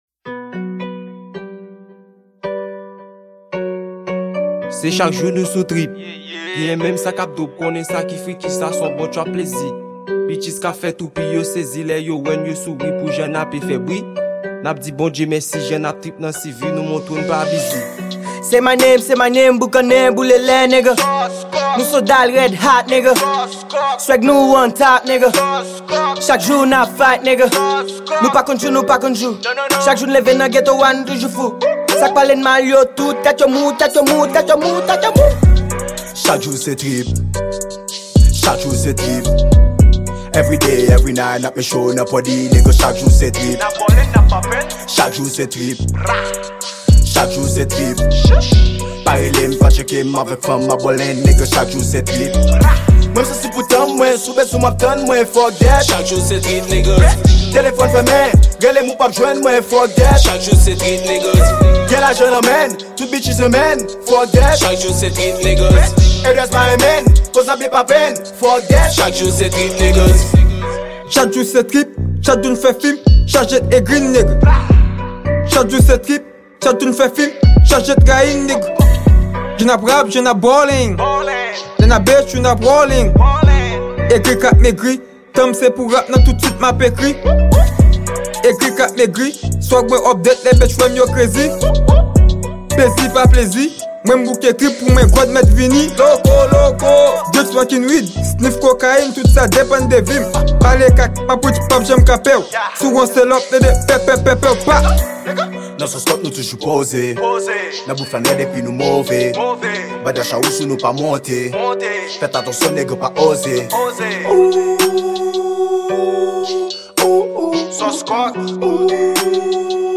Genre: Trap.